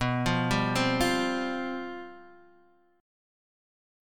Csus/B chord